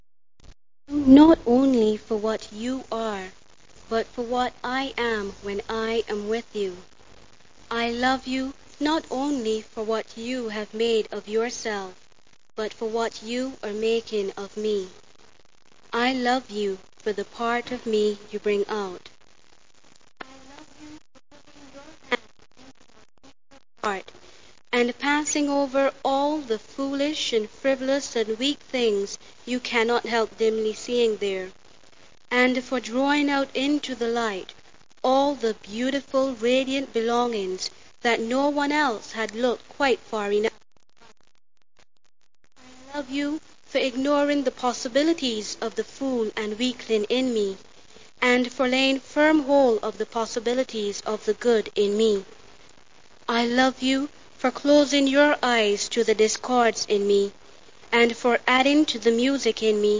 Children's Party Pageant of Youth Graduation Ceremony of the St.Leonard's Girls 16.7.86 Sample